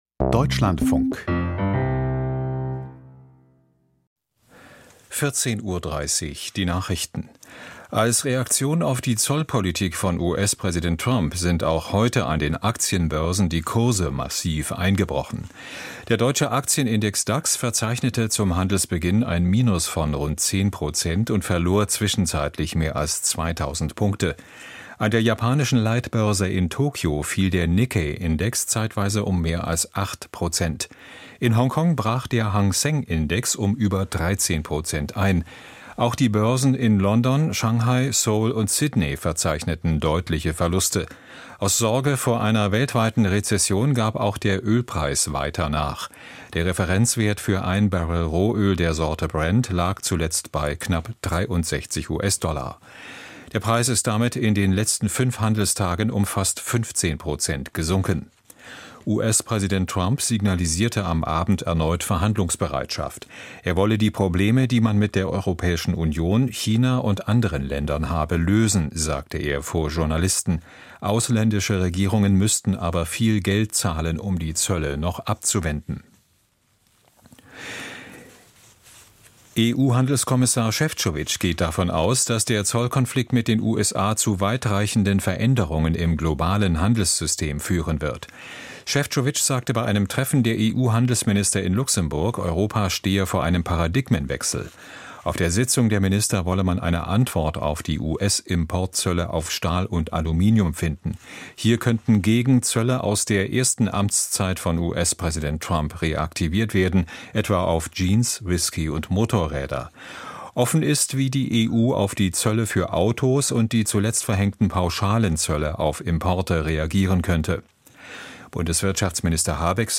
Die Deutschlandfunk-Nachrichten vom 07.04.2025, 14:30 Uhr